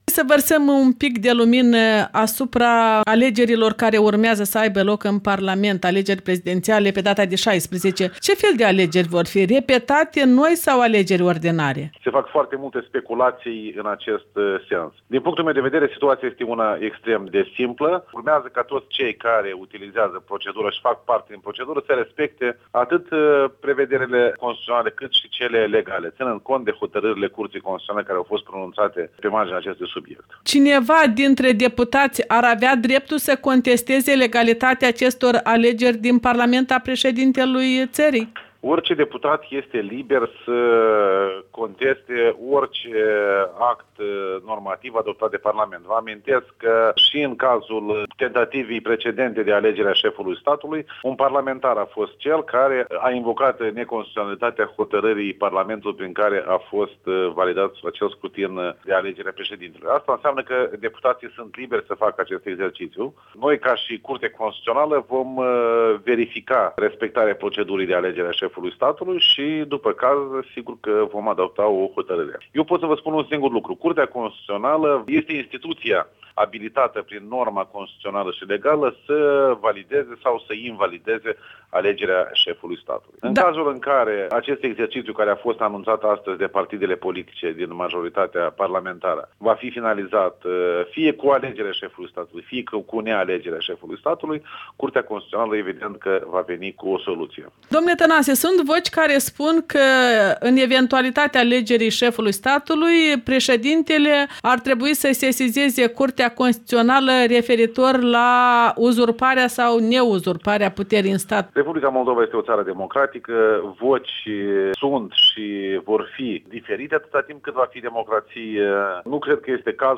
Interviu cu Alexandru Tănase, preşedintele Curții Constituționale.